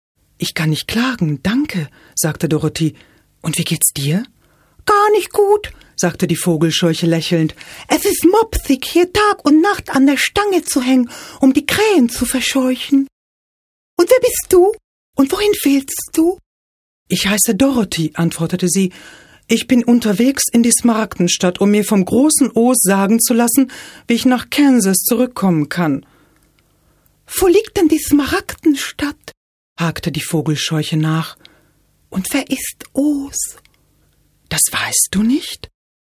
Kein Dialekt
Sprechprobe: eLearning (Muttersprache):
hungarian female voice over artist